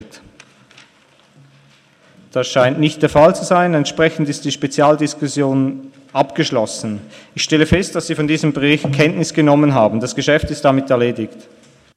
Session des Kantonsrates vom 12. und 13. Juni 2017